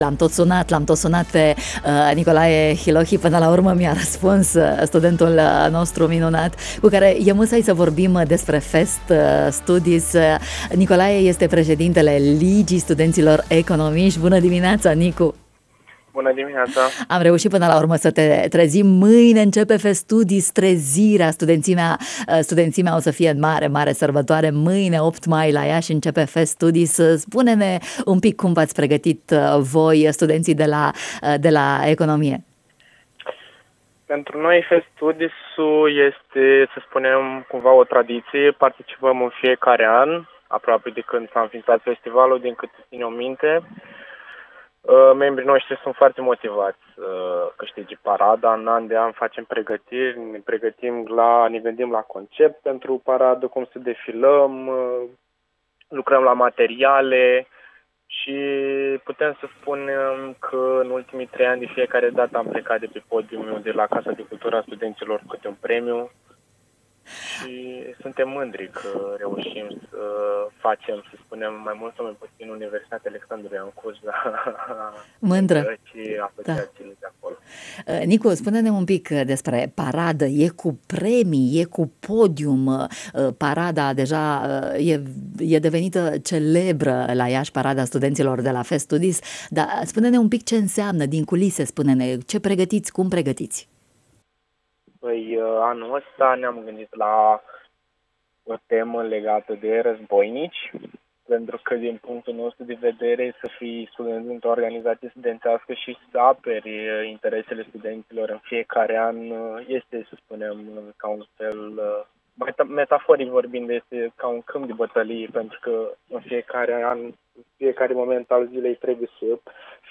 vorbeşte în matinalul Bună Dimineaţa de la Radio Iaşi